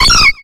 Cri de Mélo dans Pokémon X et Y.